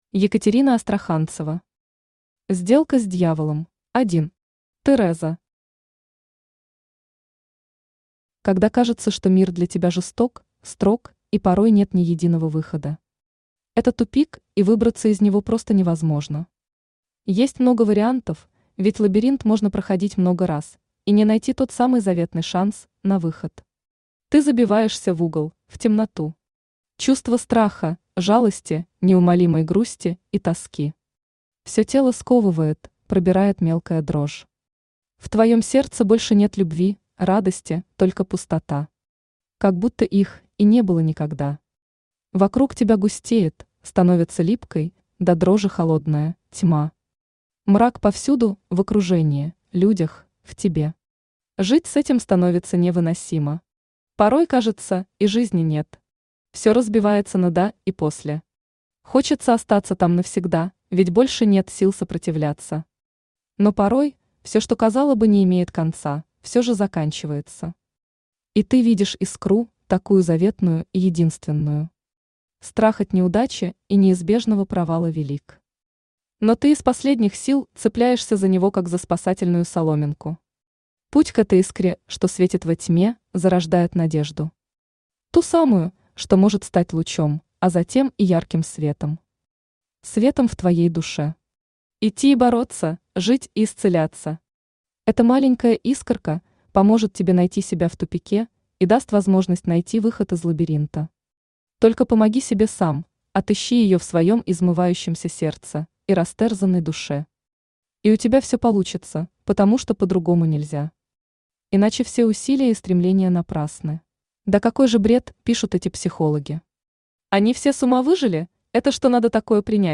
Аудиокнига Сделка с дьяволом | Библиотека аудиокниг
Aудиокнига Сделка с дьяволом Автор Екатерина Астраханцева Читает аудиокнигу Авточтец ЛитРес.